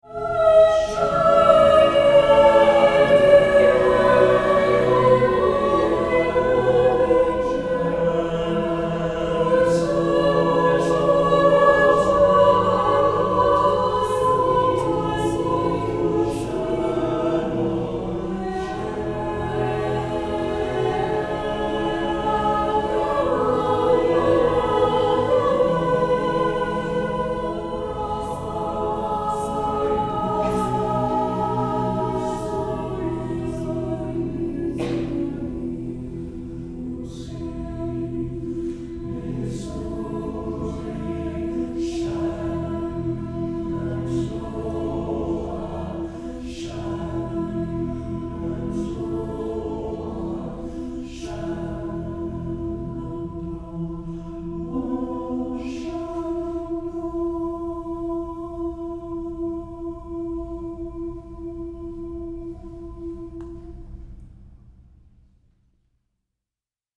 Garnet Singers is a select, mixed-voice ensemble of approximately 24 students.
Recorded at the Spring 2015 Concert
garnet-singers-sing-shenandoah.mp3